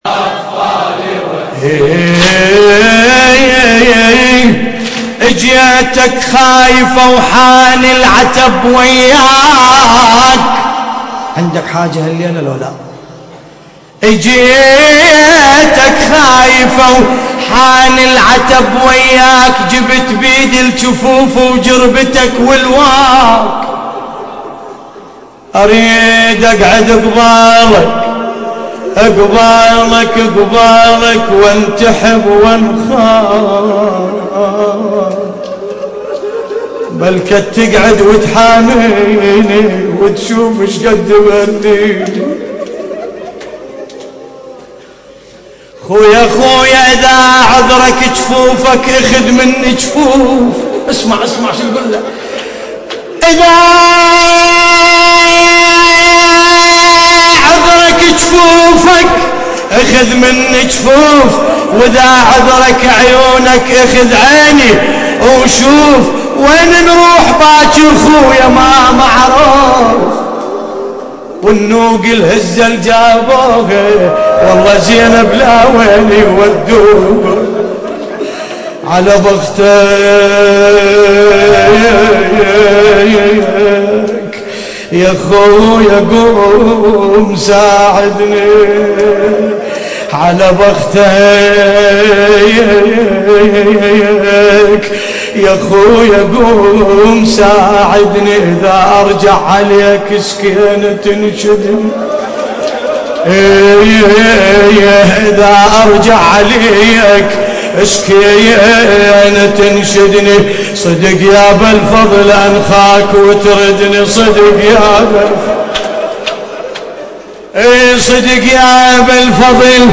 نعي : اجيتك خايفة